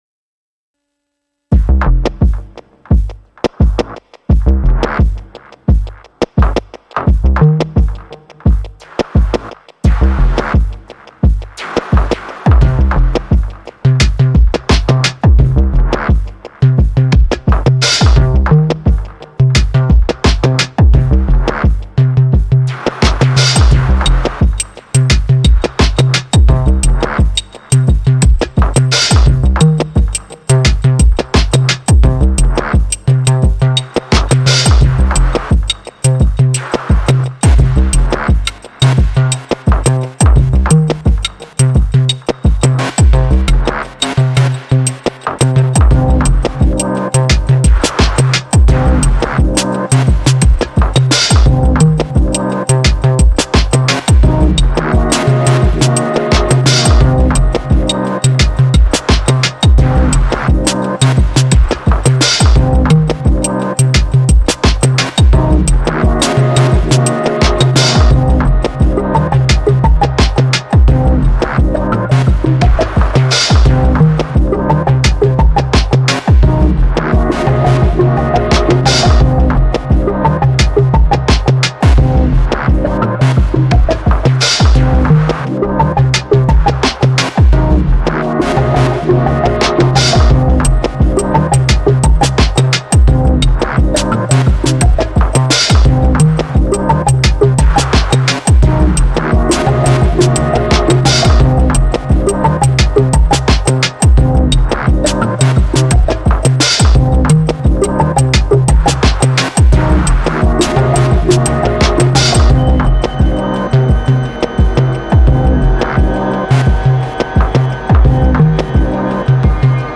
There isn’t a better feeling when song you make puts you in that meditative dream like space just by using sounds.
They are not “clubby”/house, more downtempo like I like it :yum: